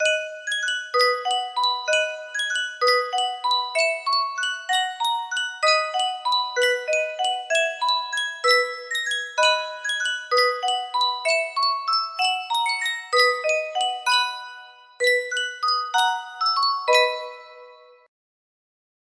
Sankyo Music Box - Silent Night 6H music box melody
Full range 60